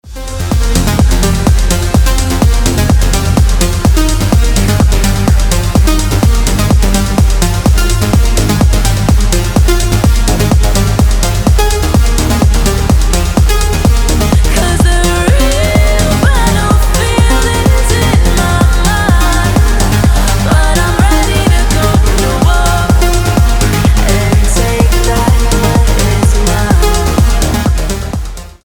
• Качество: 320, Stereo
Electronic
EDM
басы
энергичные
красивый женский голос
Стиль: транс